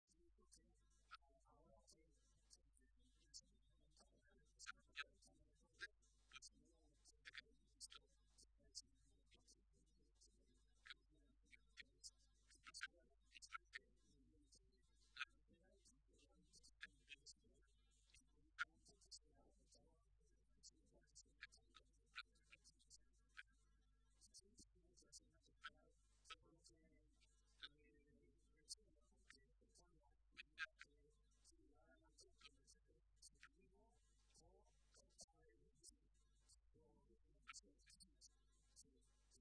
José Molina, viceportavoz del Grupo Parlamentario Socialista
Cortes de audio de la rueda de prensa